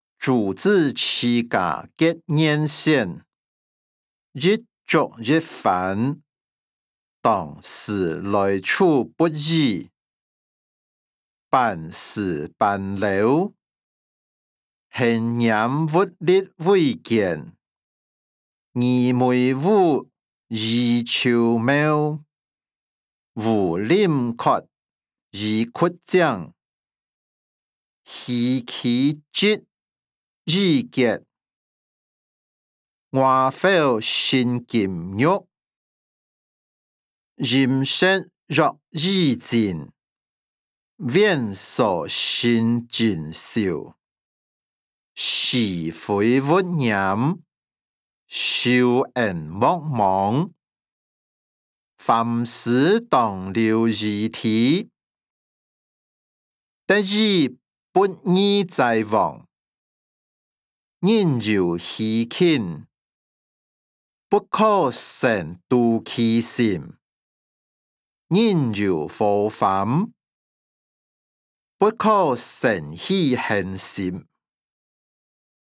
歷代散文-朱子治家格言選音檔(饒平腔)